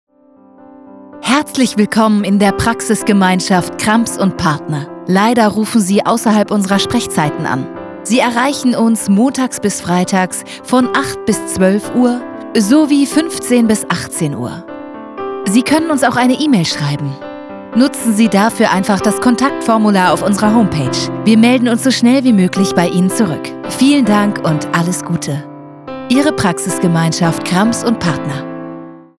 ContraltoHaute